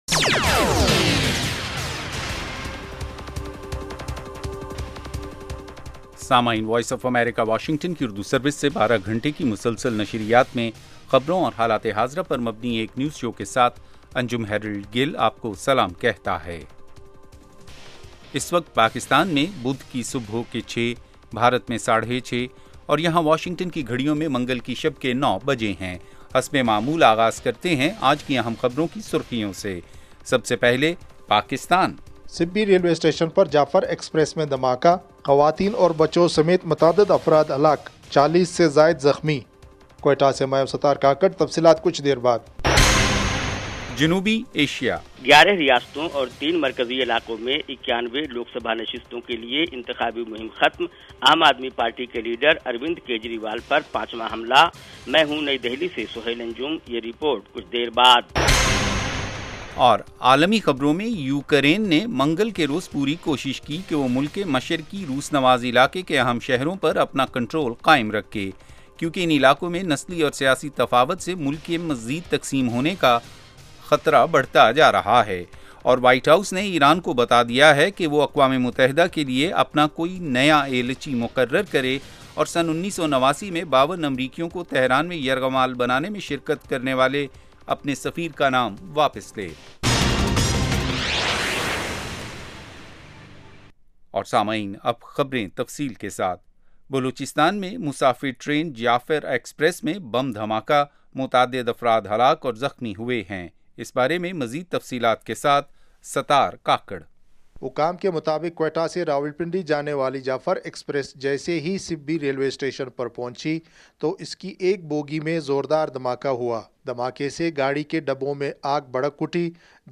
اس ایک گھنٹے کے پروگرام میں دن بھر کی اہم خبریں اور پاکستان اور بھارت سے ہمارے نمائندوں کی رپورٹیں پیش کی جاتی ہیں۔ اس کے علاوہ انٹرویو، صحت، ادب و فن، کھیل، سائنس اور ٹیکنالوجی اور دوسرے موضوعات کا احاطہ کیا جاتا ہے۔